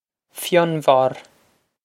Fionbharr Fyun-var
Pronunciation for how to say
This is an approximate phonetic pronunciation of the phrase.